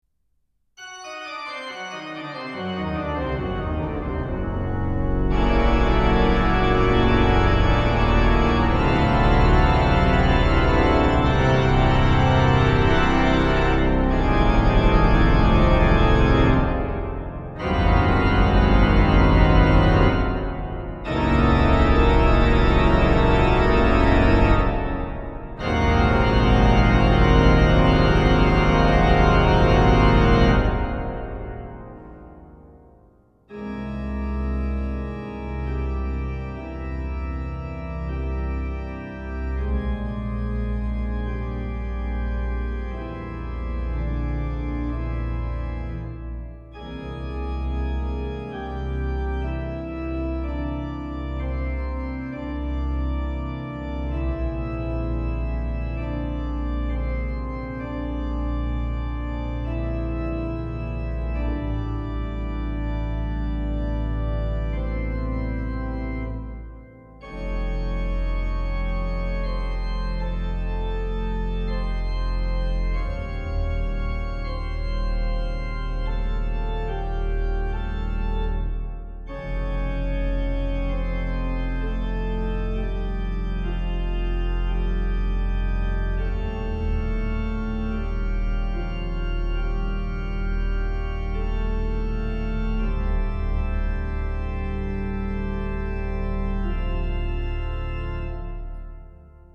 CHORAL MUSIC
Version No.1 for SATB Chorus and Organ.
Version No.2 for Unison Chorus and Organ.
Version No.3 for Solo Organ.